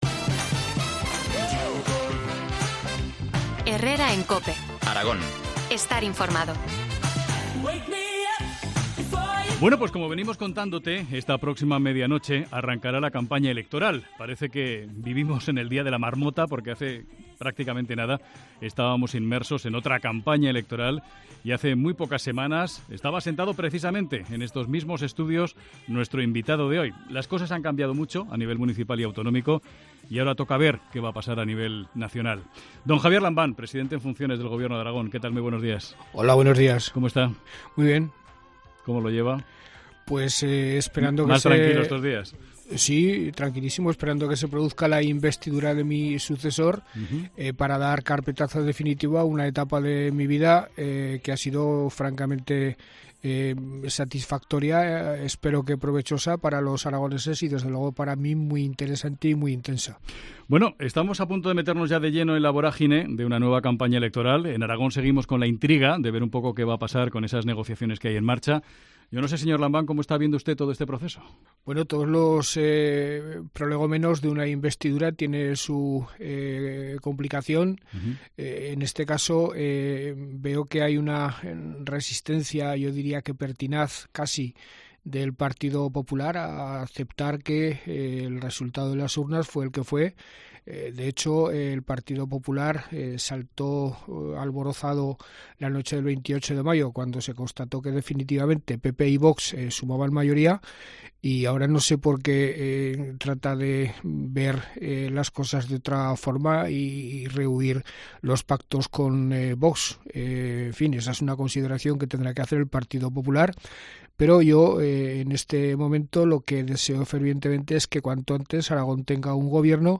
Entrevista al presidente del Gobierno de Aragón en funciones, Javier Lambán.